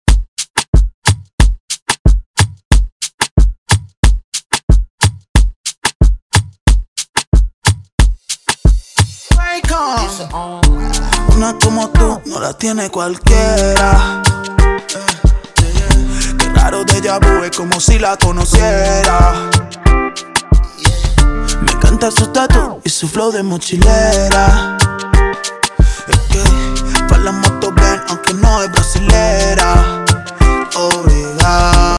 His style is unique and electrifying.
DJ